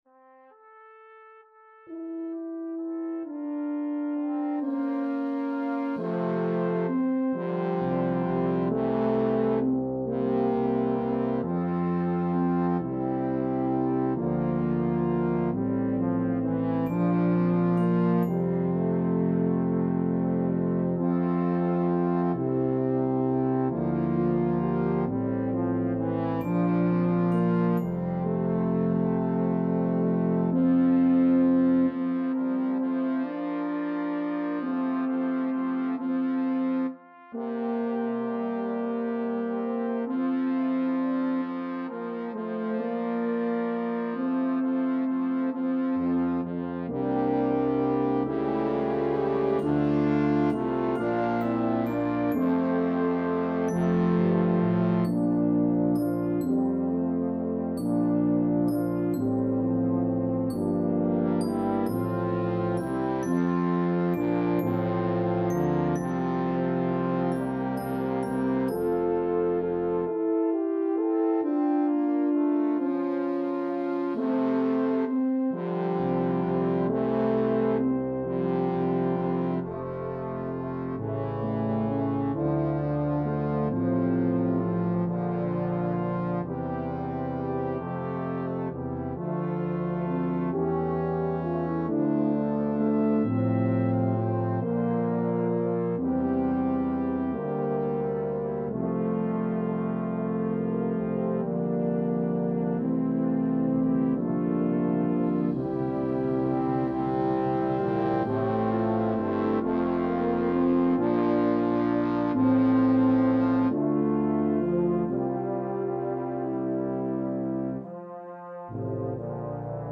(Hymn Tune Arrangement)